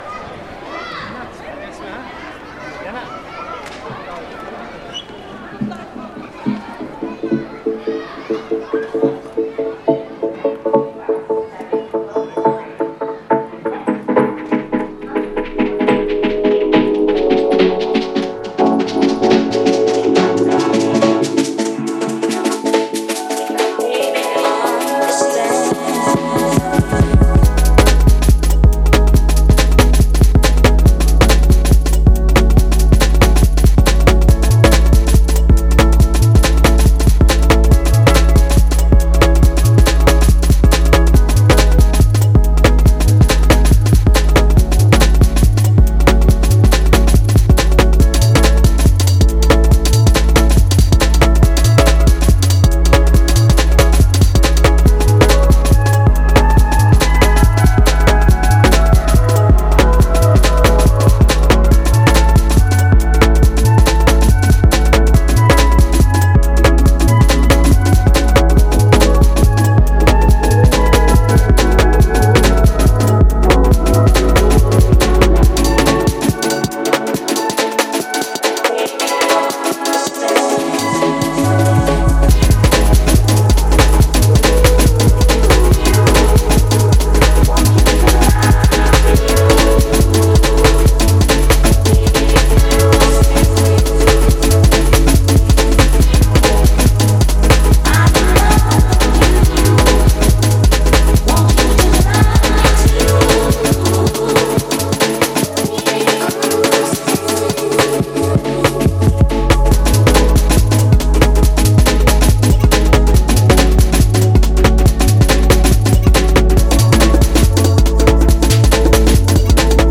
электронной музыки
В общем, - балдеж электронный.